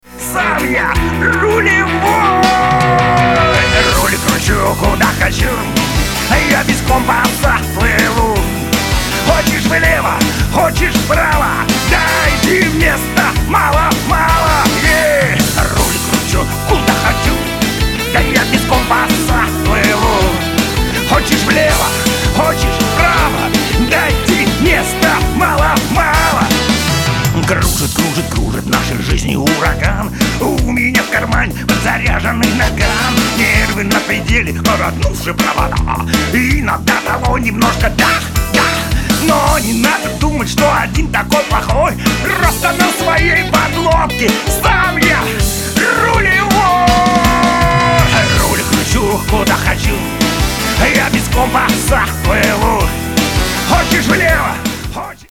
• Качество: 320, Stereo
рок